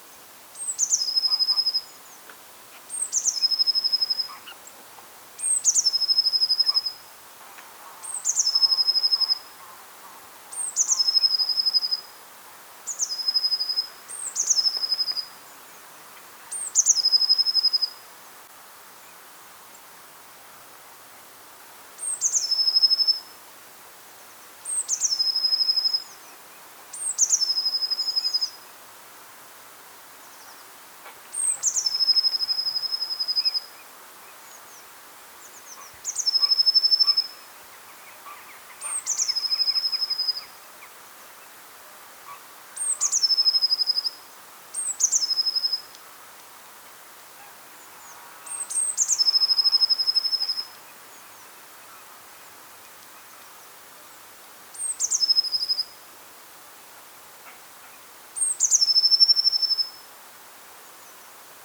pimpelmees
🇬🇧 English: blue tit
🔭 Wetenschappelijk: Parus caeruleus
♫ zang
pimpelmees zang.mp3